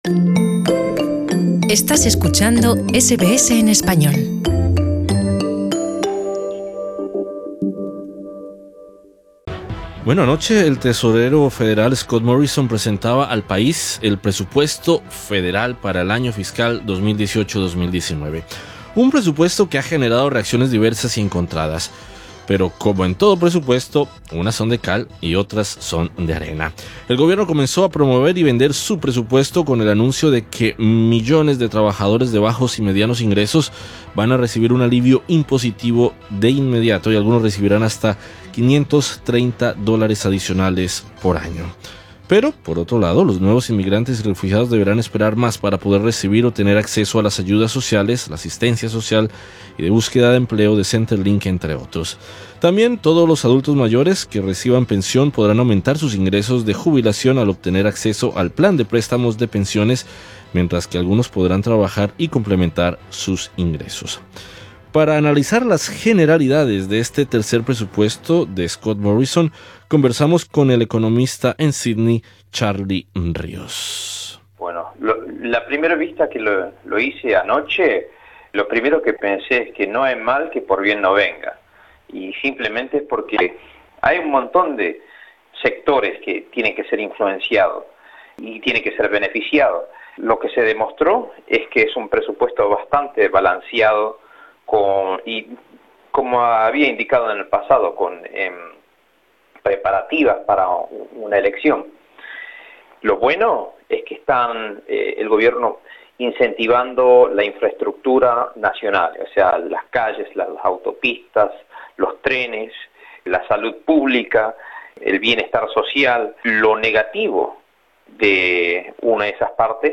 Entrevista con el economista